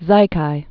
(zīkī)